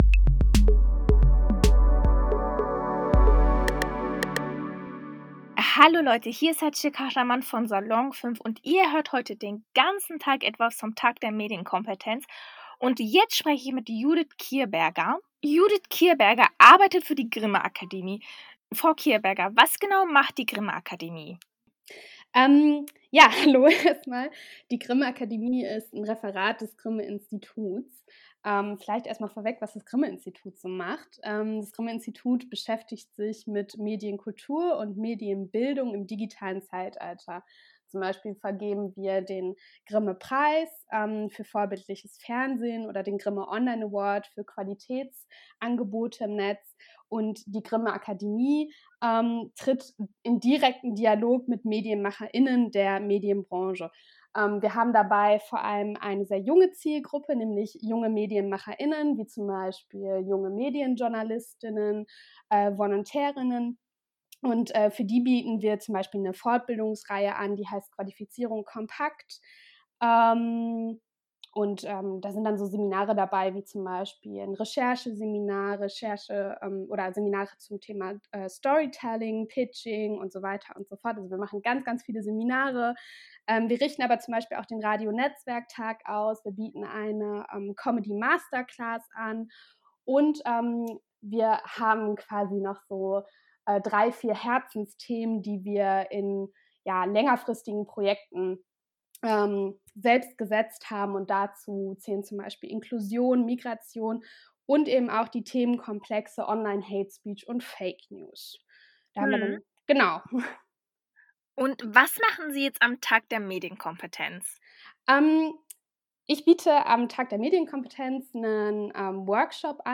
Einen ganzen Tag lang stellte die Jugendredaktion Salon5 den Tag der Medienkompetenz im eigenen Webradio vor.
• Gespräch mit